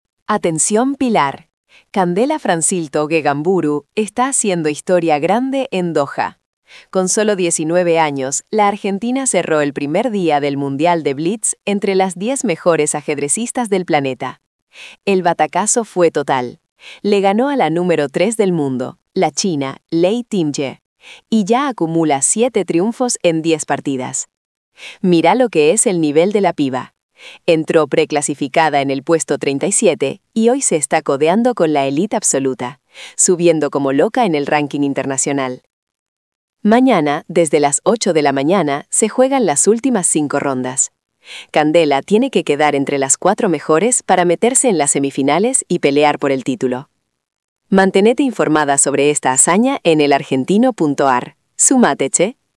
— 🎙 Resumen de audio generado por IA.